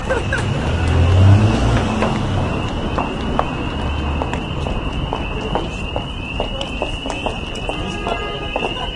布列塔尼森林氛围1
描述：用Yeti Blue麦克风录制，在Vegas Pro中处理。 48kHz的/ 16位。
Tag: 风声 鸟声 河流 森林 森林 性质 现场录音